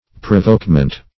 Provokement \Pro*voke"ment\, n.